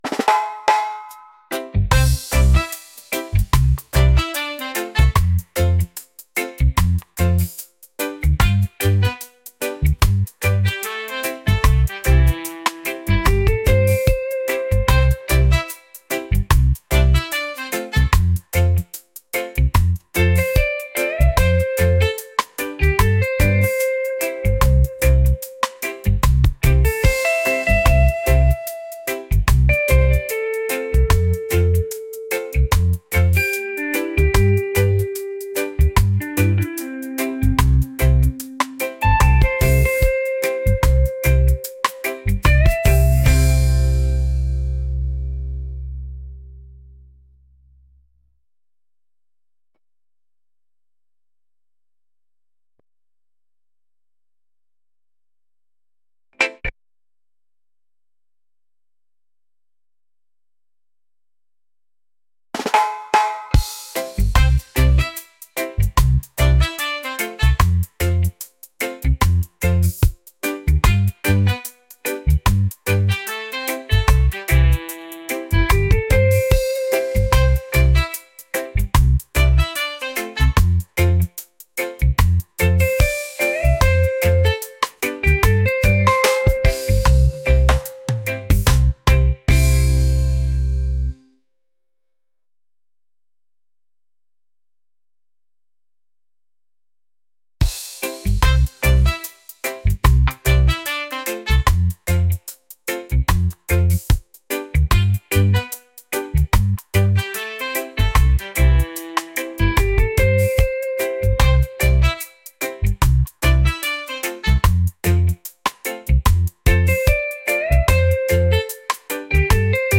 reggae | positive | vibes | laid-back